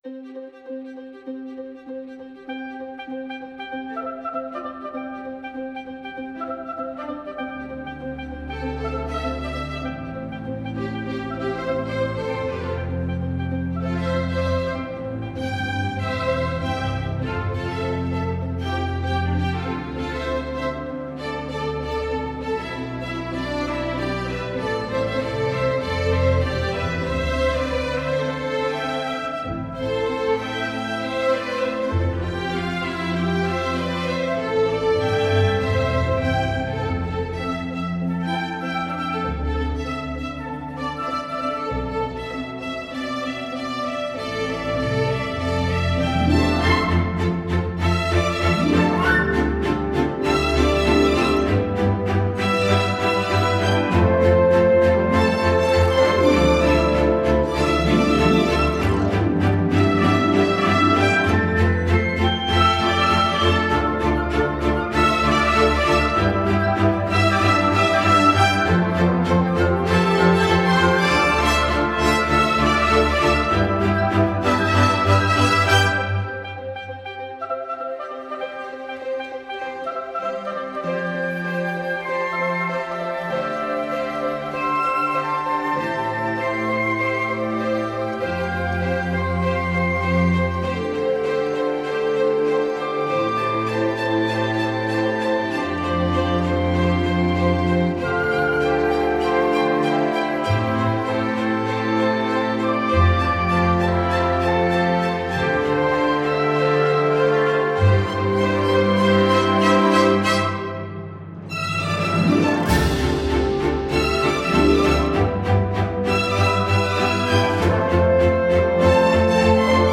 موسیقی بی کلام
instrumental